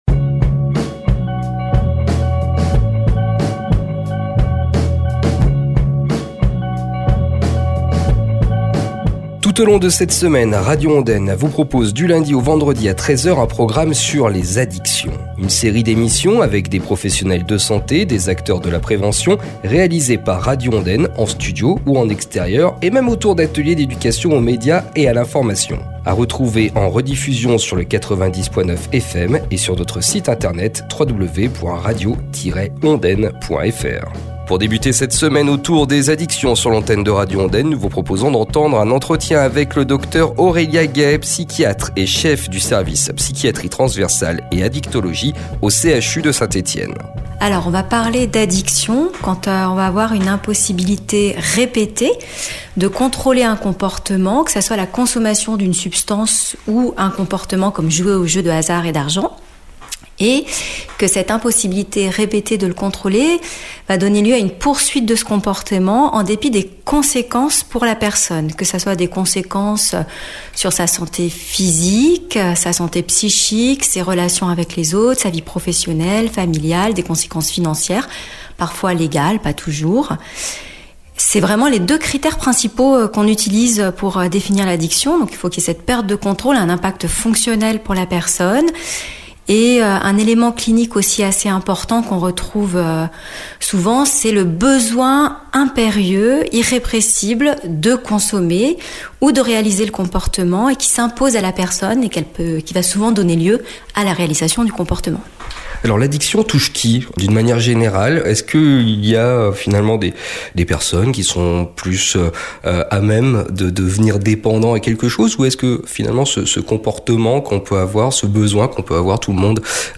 Le direct